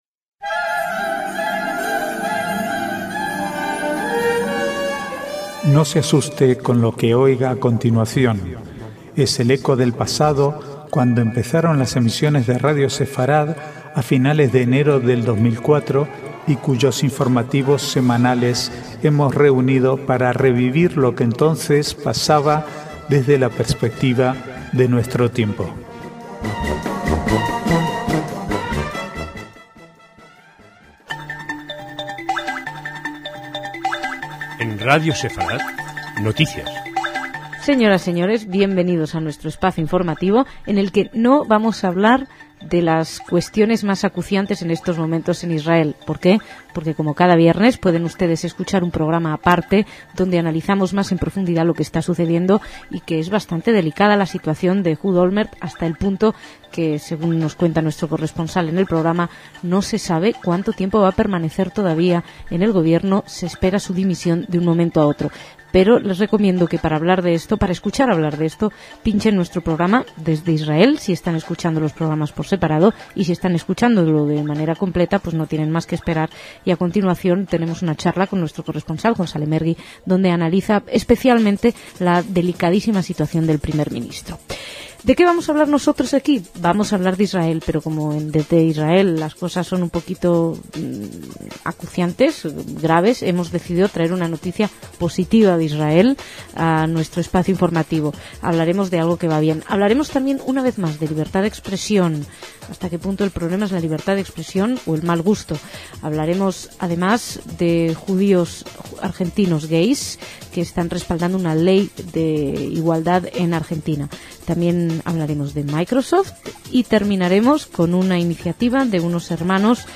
Archivo de noticias del 30/5 al 4/6/2008